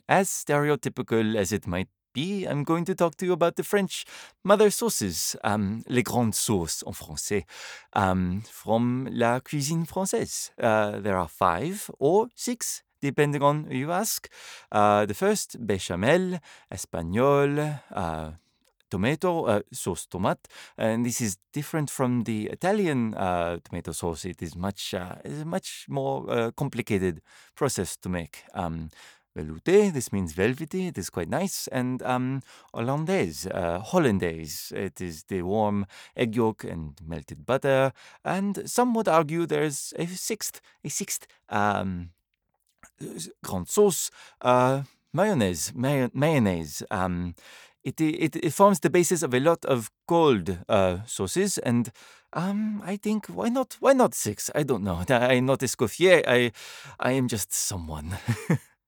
french | international
French.mp3